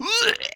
sounds_vomit_03.ogg